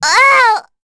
Rehartna-Vox_Damage_07.wav